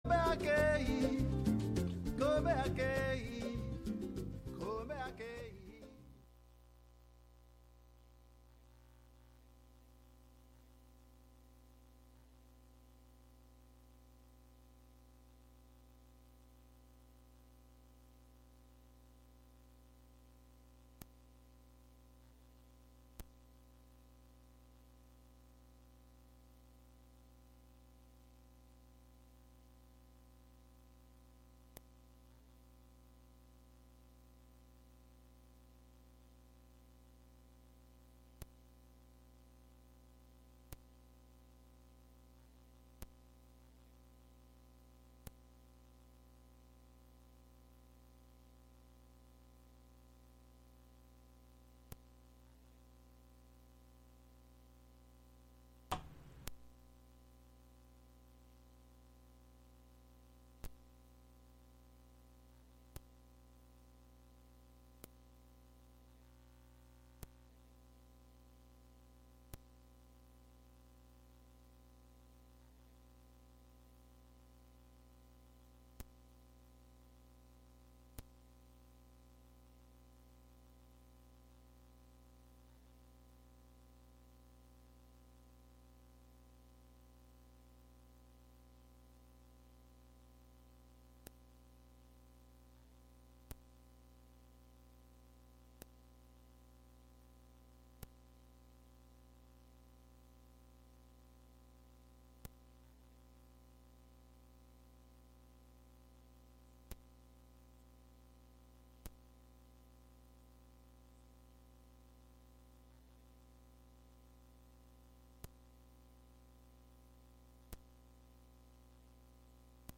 Jazz instrumentals